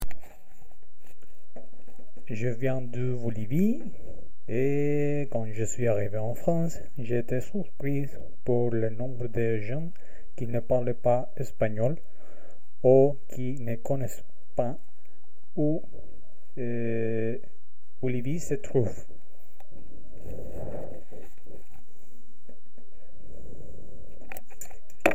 Cabine de témoignages
Témoignage du 24 novembre 2025 à 16h42